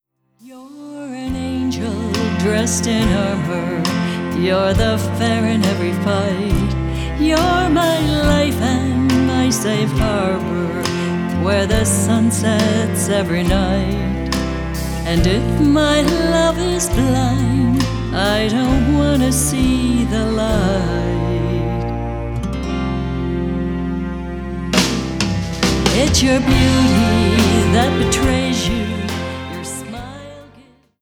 Guitars / Bass / Keys